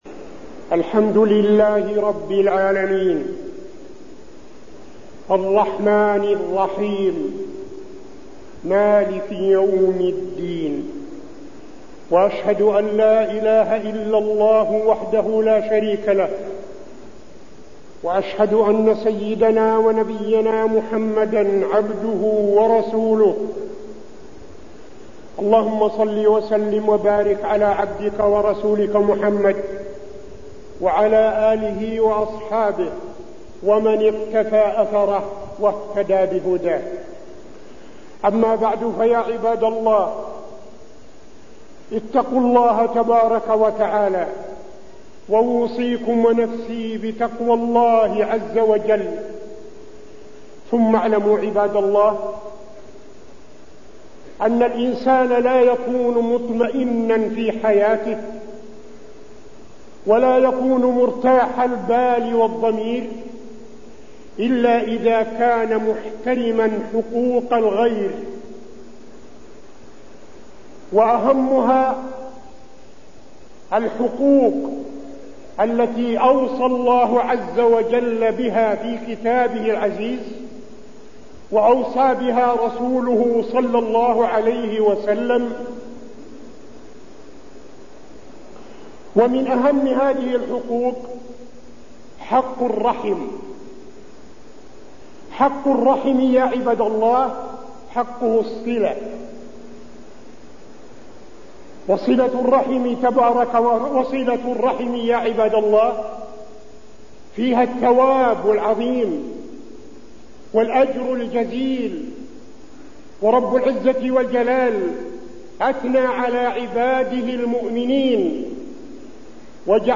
تاريخ النشر ١٤ صفر ١٤٠٧ هـ المكان: المسجد النبوي الشيخ: فضيلة الشيخ عبدالعزيز بن صالح فضيلة الشيخ عبدالعزيز بن صالح صلة الرحم